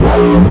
Bass 1 Shots (104).wav